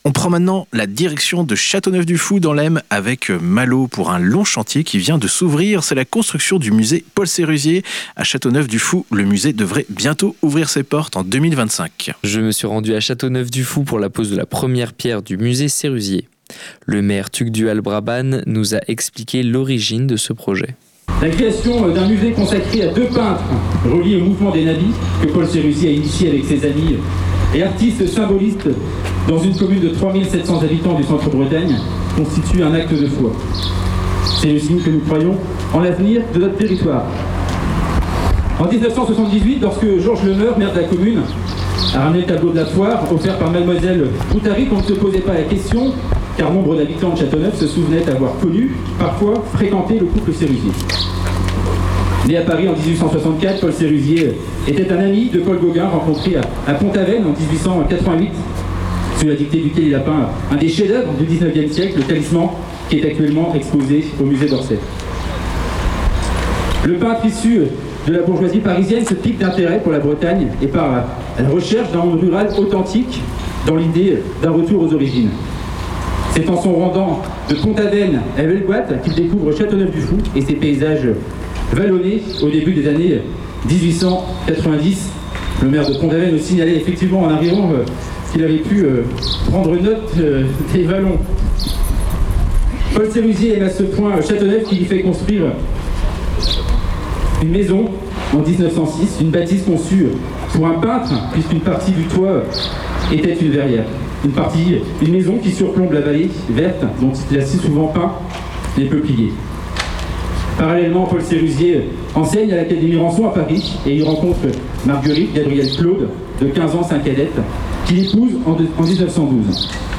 Pose de la première pierre du musée Sérusier à Châteauneuf-du-Faou | sur le 100.4 FM - Vous souhaitez partager une information ?